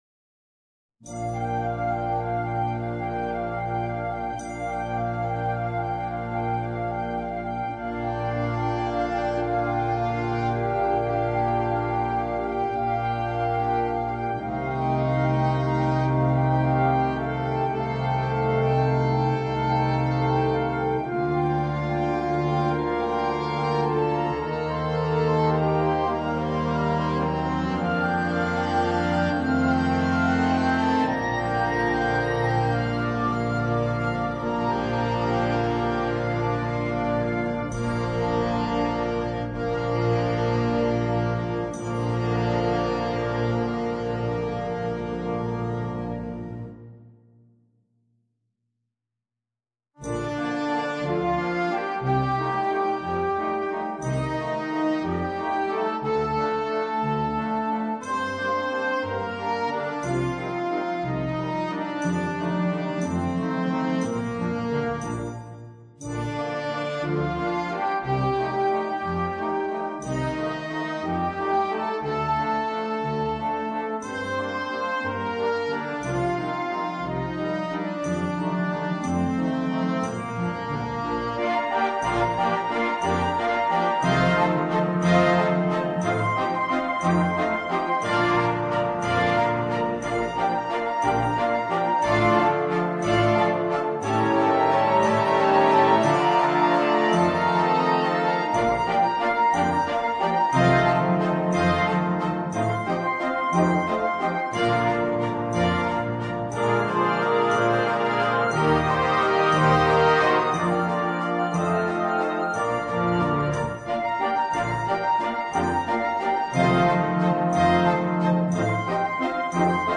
MUSICA PER BANDA
Concert valzer